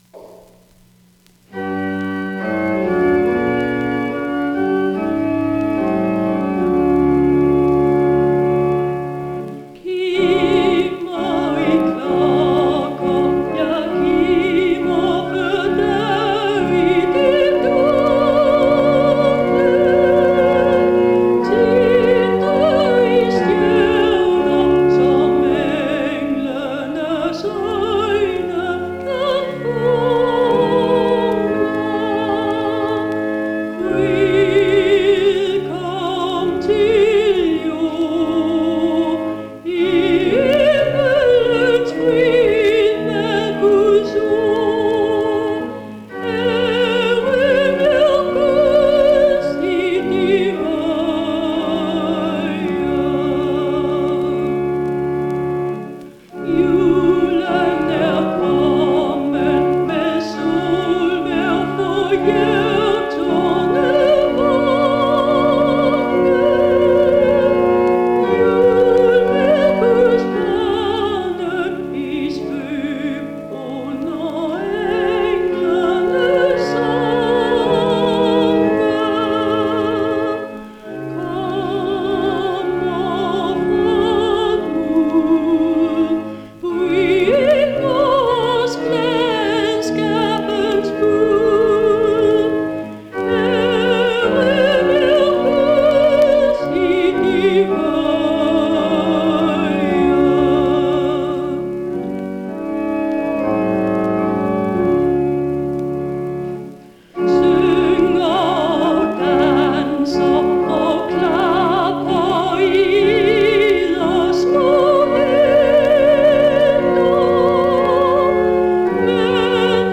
Concert Soprano